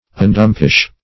Search Result for " undumpish" : The Collaborative International Dictionary of English v.0.48: Undumpish \Un*dump"ish\, v. t. [1st pref. un- + dumpish.] To relieve from the dumps.